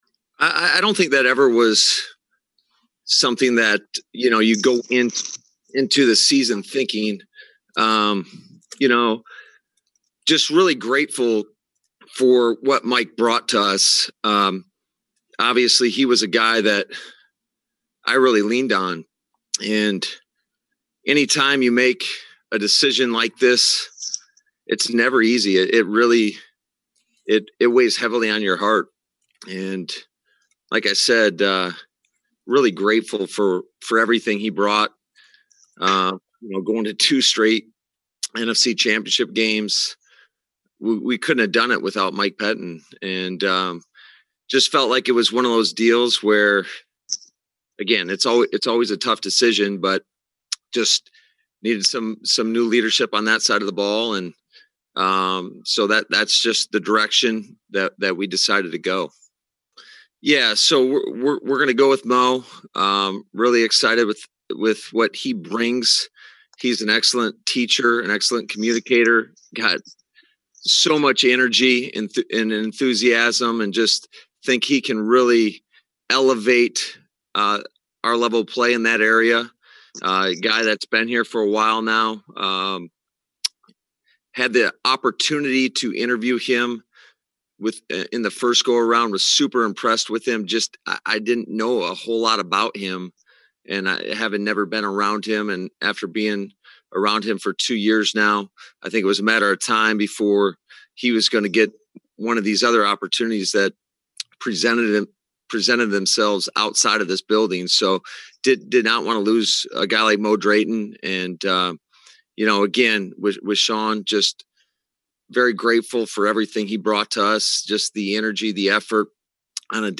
LaFleur went first in the season ending Zoom session and spoke for just over 28 minutes.